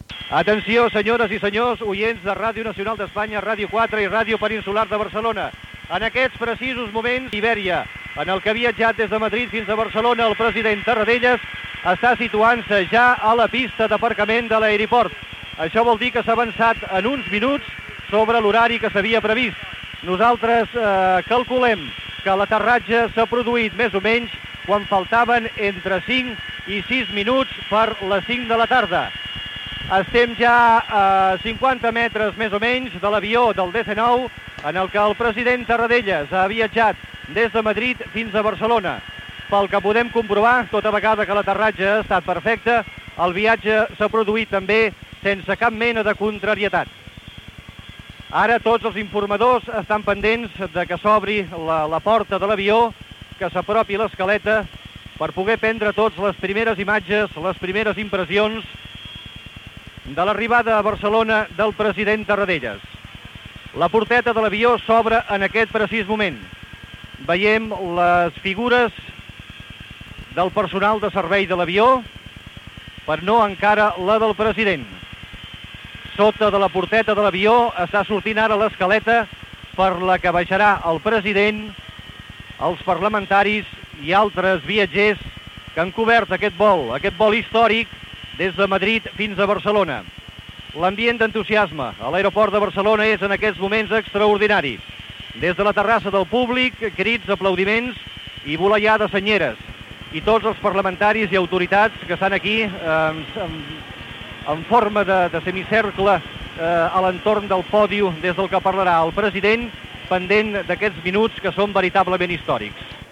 Transmissió del retorn del president de la Generalitat Josep Tarradellas. Aterratge de l'avió a l'aeroport del Prat.
Informatiu